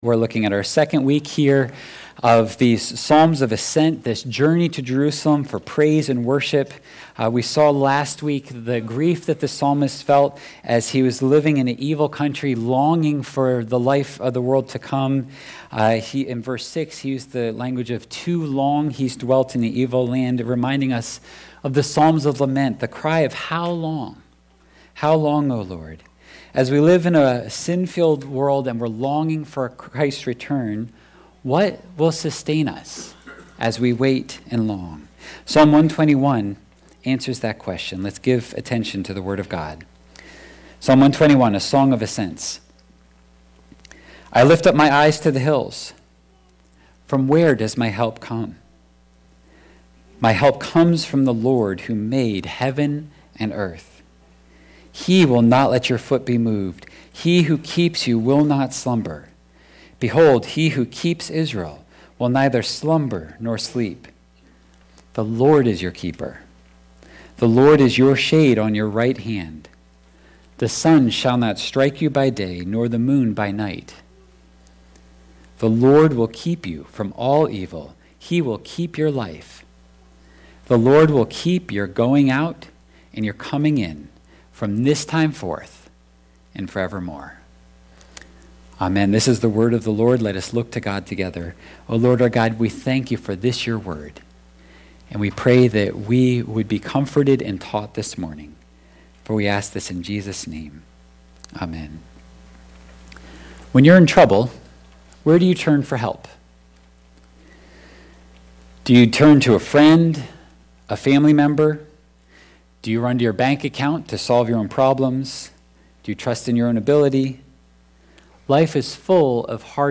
8.3.25-sermon-audio.mp3